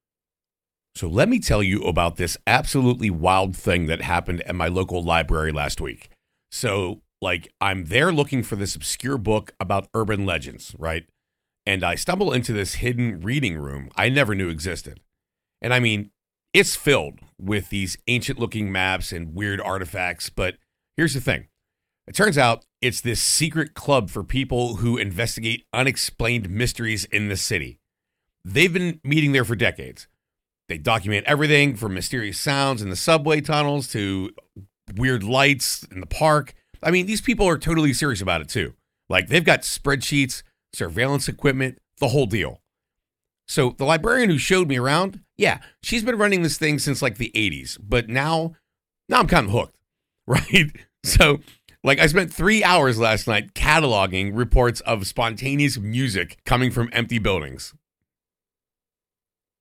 Male American English Conversational Storytelling for AI Dataset